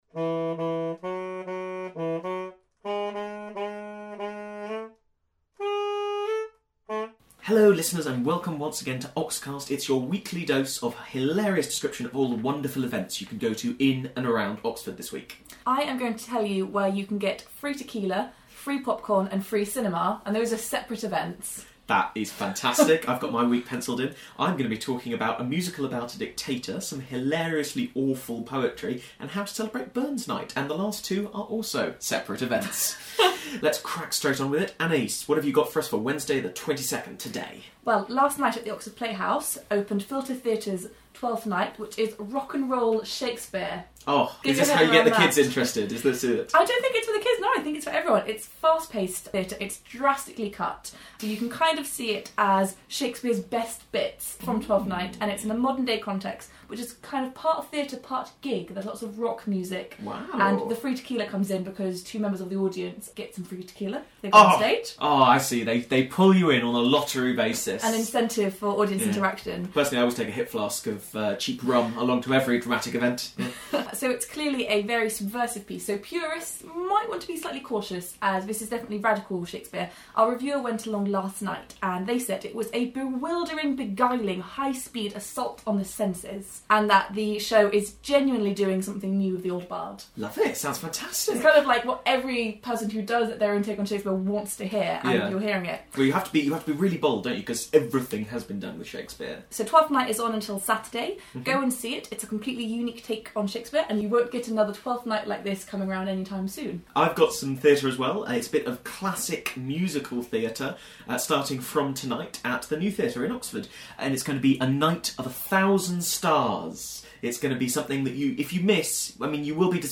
The Daily Info team let you know about the best events happening in and around Oxford with humour, anecdotes and bad Scottish accents. Featuring the best and worst of poetry, the former Archbishop of Canterbury and Ron Burgundy.
Bad Scottish Accents and Mashed Up Theatrics